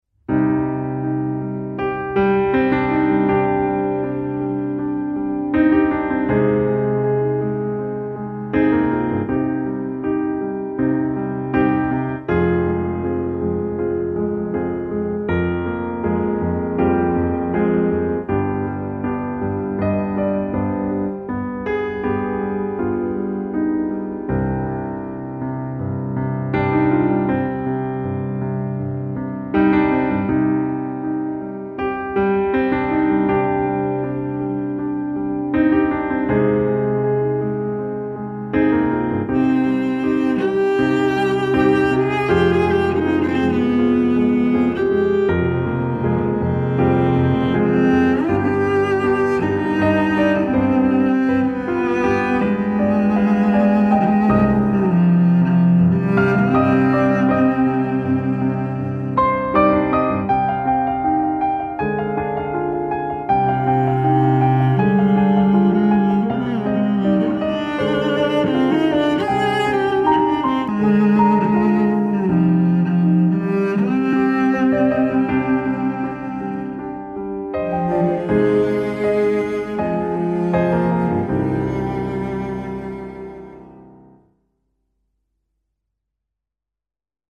piano - intimiste - romantique - aerien - melodieux